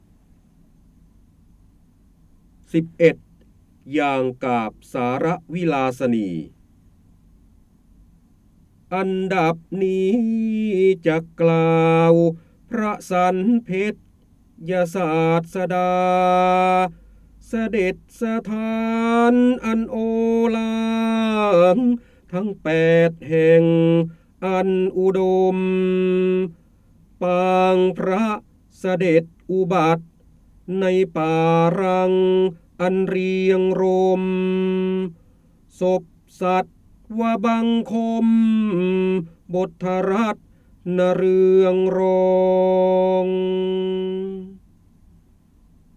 เสียงบรรยายจากหนังสือ จินดามณี (พระโหราธิบดี) ๑๑อย่างกาพย์สารวิลาสนี
คำสำคัญ : ร้อยกรอง, การอ่านออกเสียง, พระโหราธิบดี, ร้อยแก้ว, จินดามณี, พระเจ้าบรมโกศ